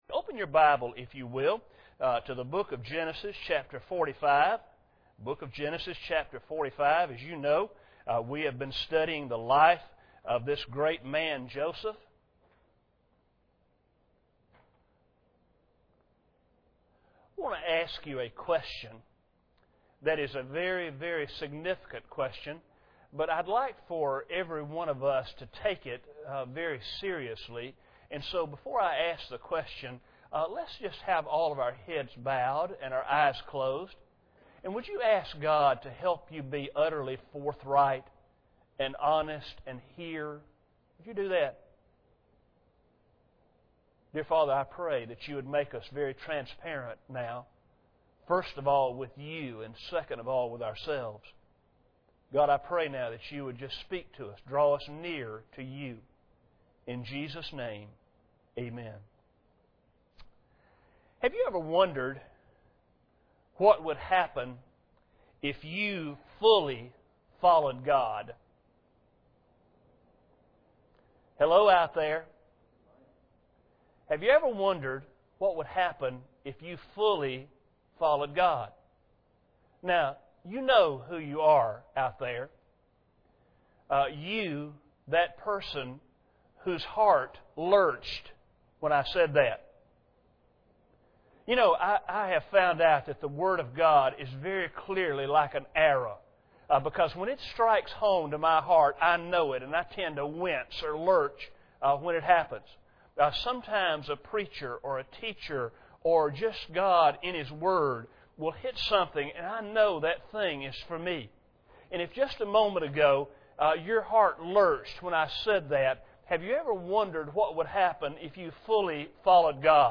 Passage: Genesis 45:1-28 Service Type: Sunday Evening Bible Text